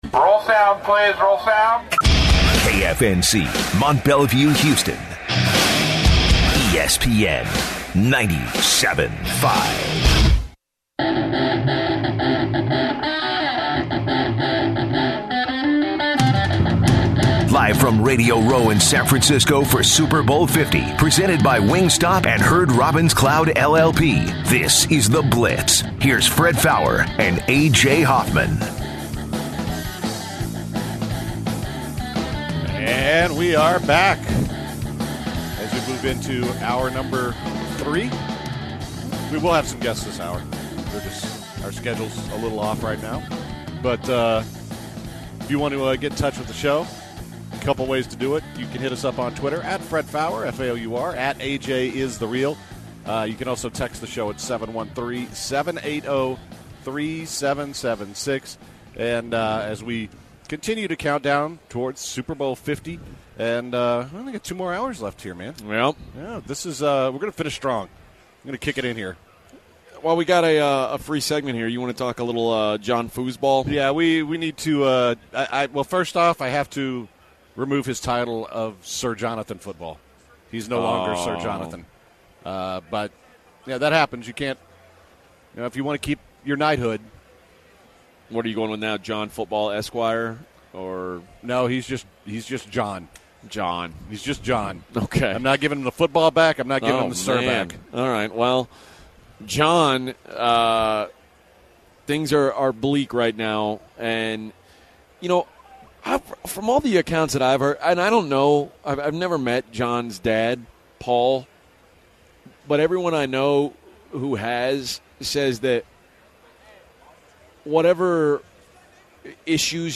begin the third hour of the Blitz live from San Francisco.
Indianapolis Colts Head Coach Chuck Pagano joins the Blitz.
Actor Cuba Gooding Jr. joins the Blitz to discuss his new role as O.J. Simpson in The People vs. O.J. Simpson. Kansas City Chiefs running back and former Texas Longhorns Jamaal Charles joins the Blitz to close the third hour.